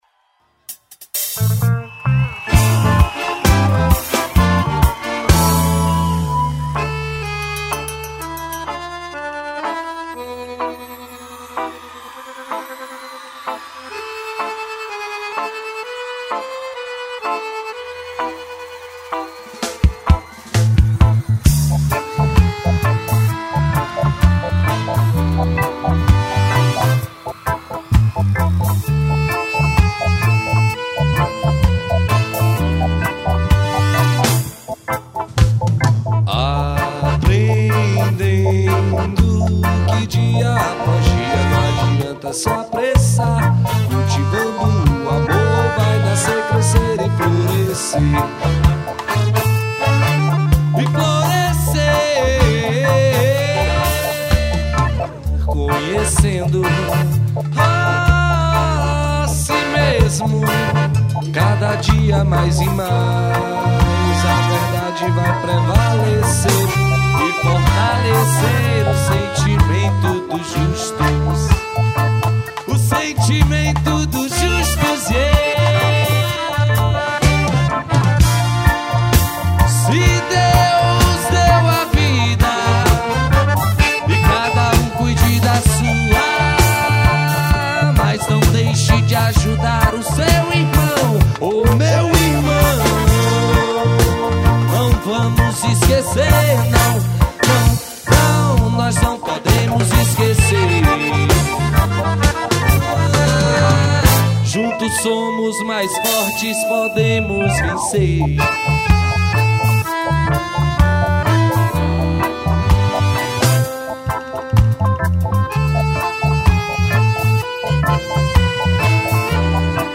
AO VIVO
2120   04:12:00   Faixa:     Reggae
Guitarra, Vocal
Bateria
Trompete, Vocal
Sax Alto
Baixo Elétrico 6
Escaleta, Teclados